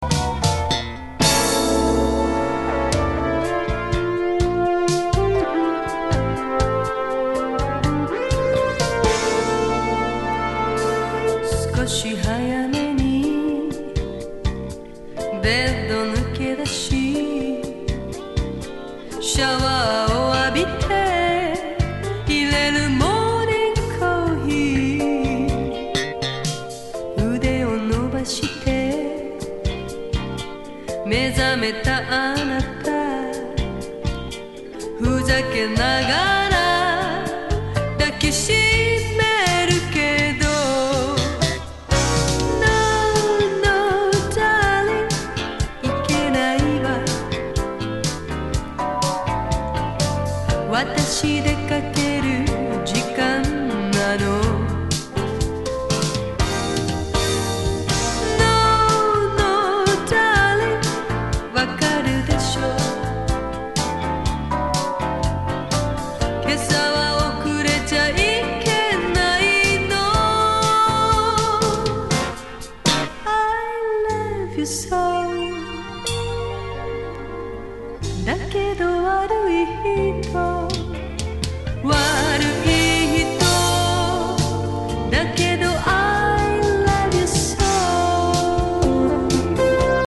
CIRY POP��LIGHT MELLOW�ϥ쥢�͵���!!